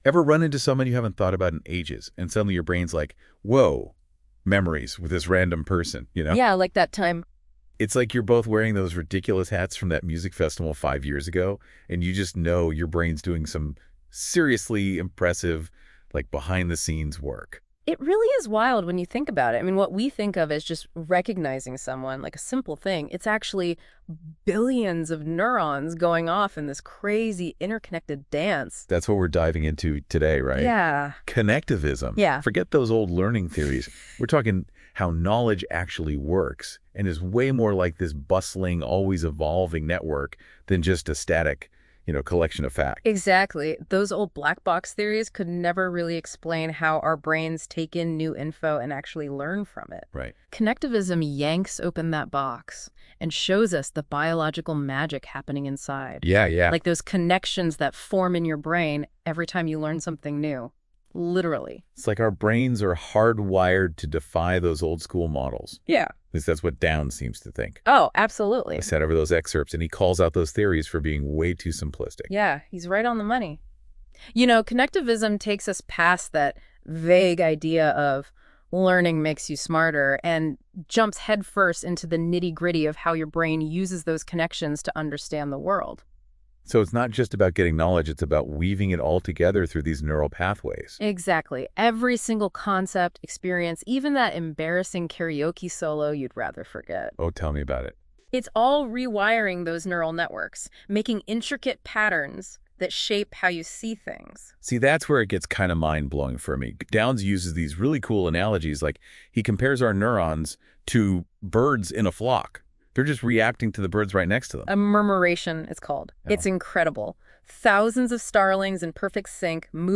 I couldn't resist tryng it with my own work on connectivism, producing my own audio in the same format.